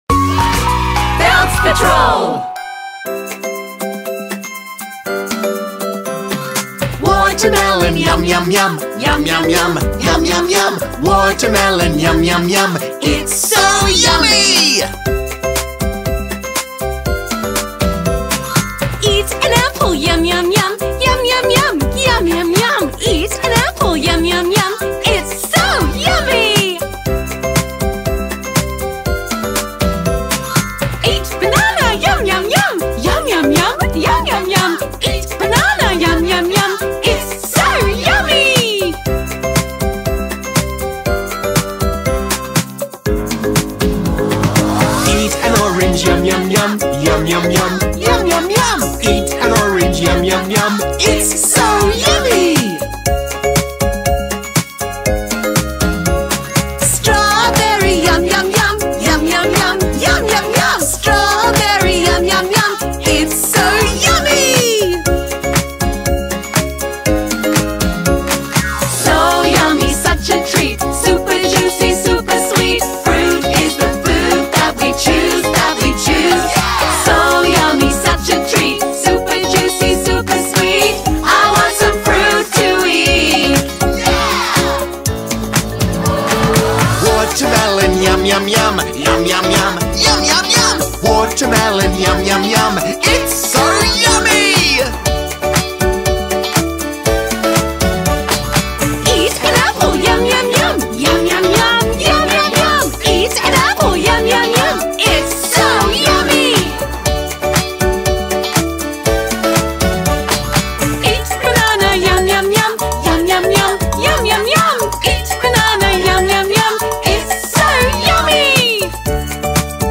Fruit_So_Yummy______________________Kids_Song.mp3